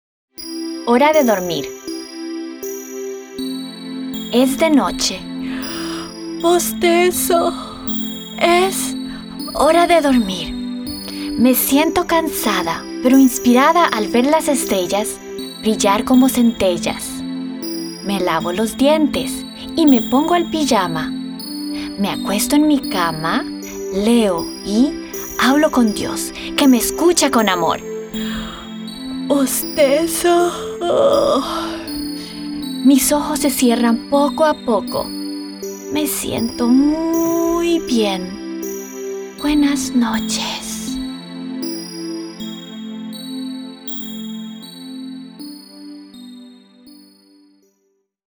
bedtimesongspanish1.wav